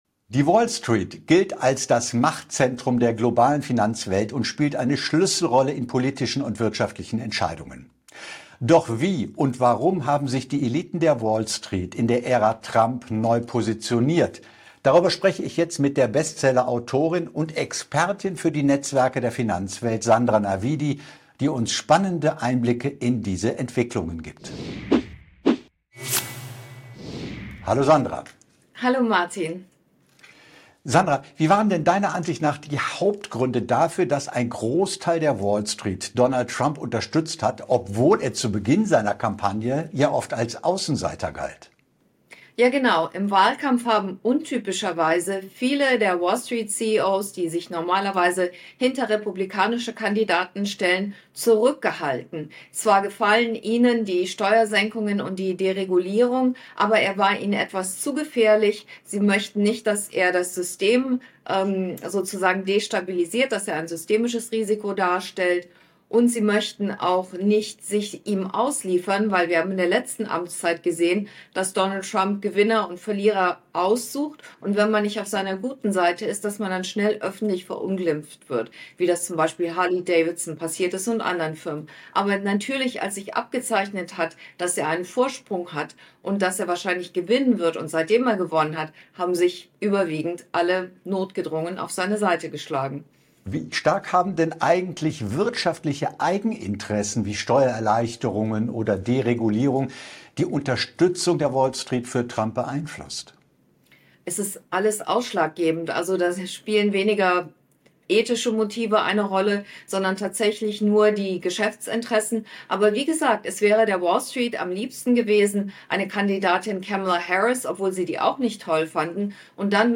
Ein aufschlussreiches Gespräch über Macht, Einfluss und die